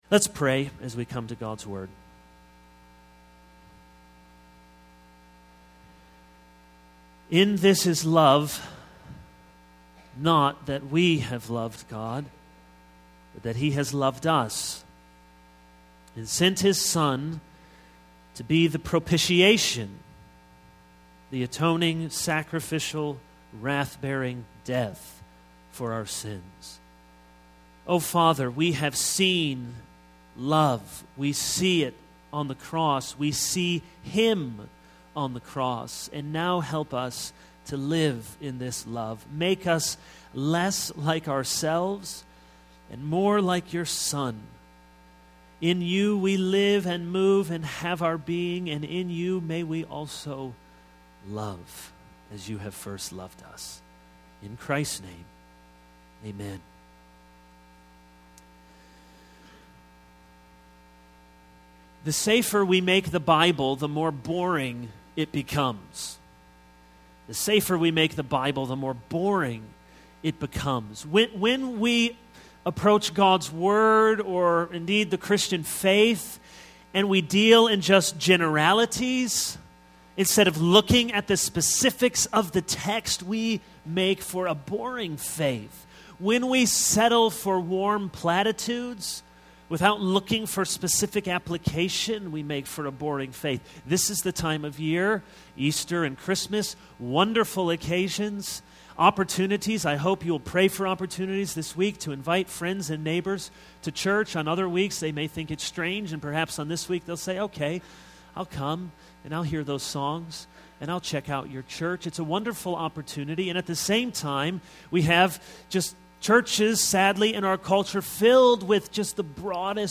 This is a sermon on 1 John 3:11-18.